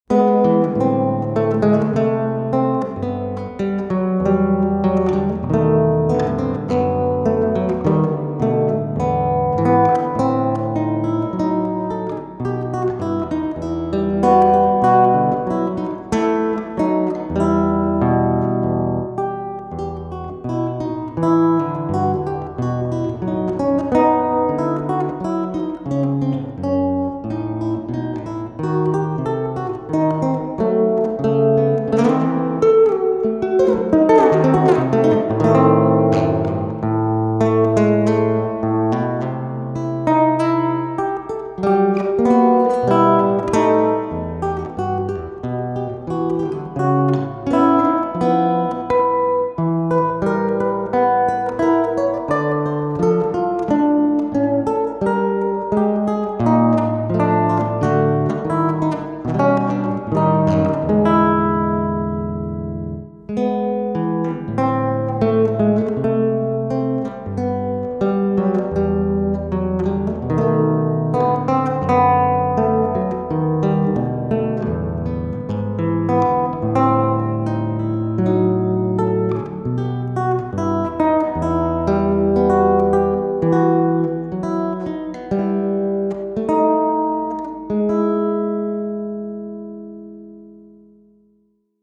Mein erstes eigenes Bourée